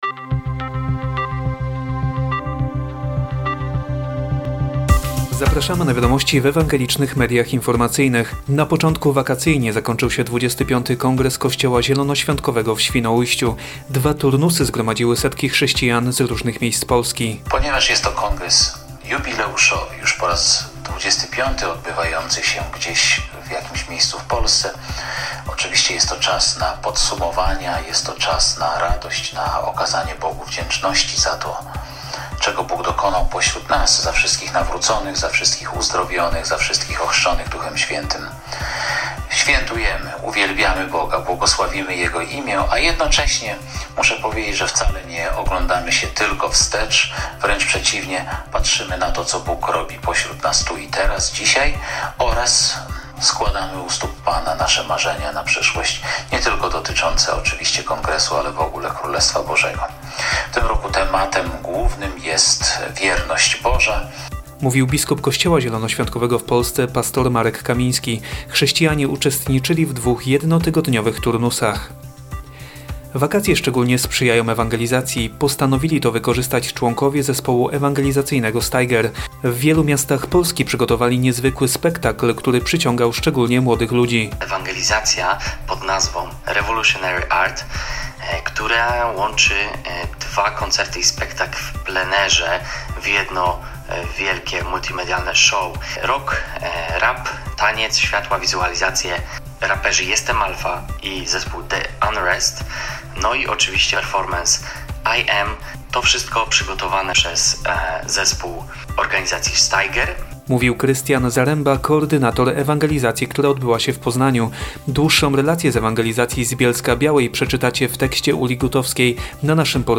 Najnowszy radiowy serwis informacyjny